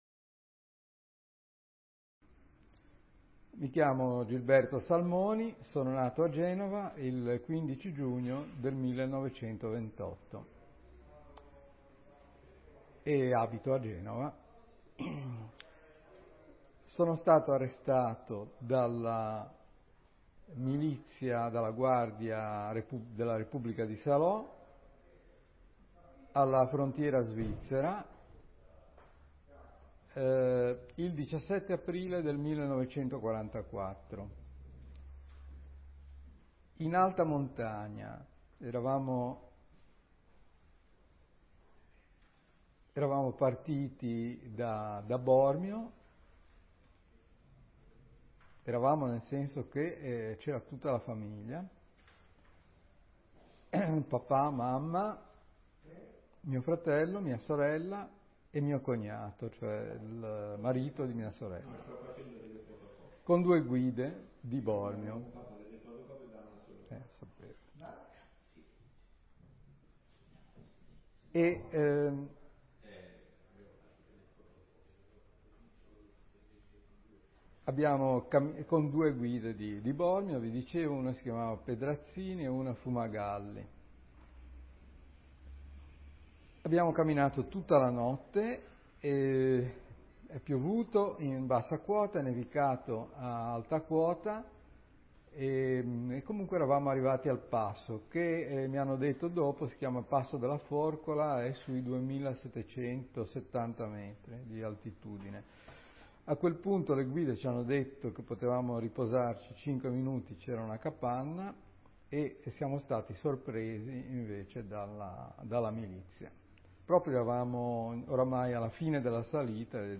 a Genova Intervista